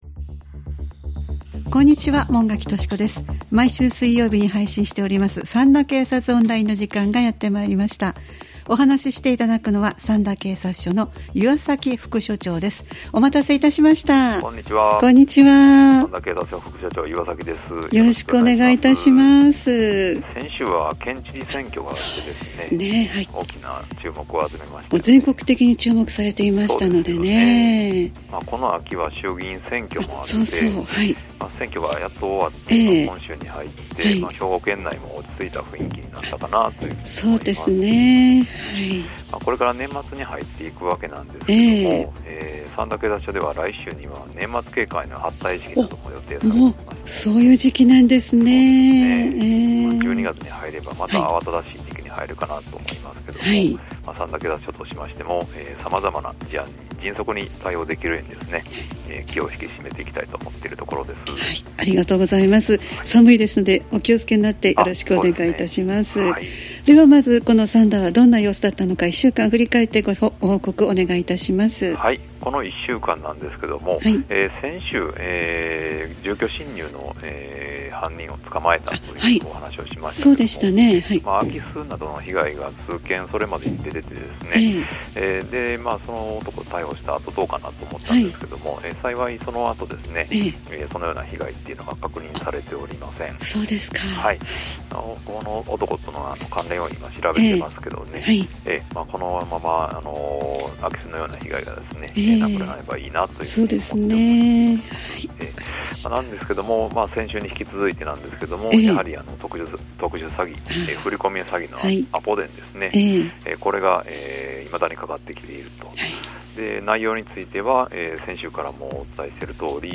三田警察署に電話を繋ぎ、一週間の事件事故、防犯情報、警察からのお知らせなどをお聞きしています（再生ボタン▶を押すと番組が始まります）